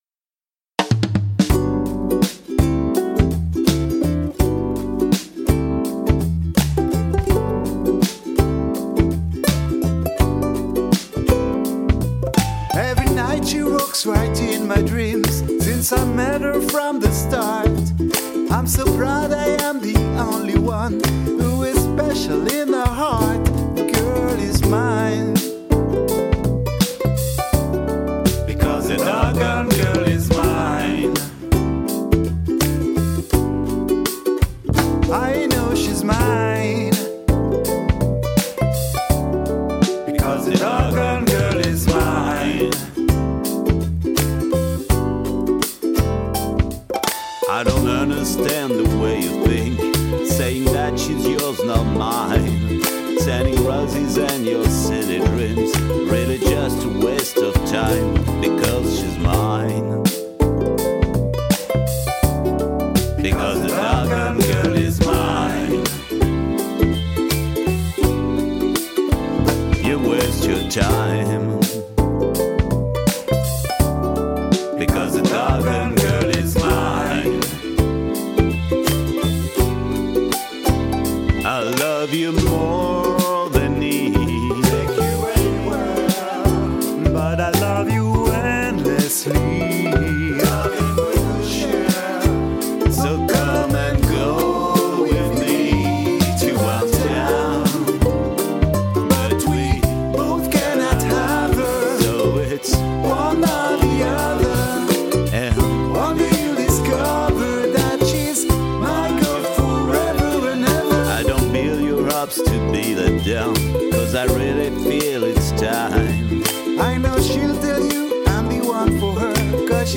Andante
ukuleleCover
duo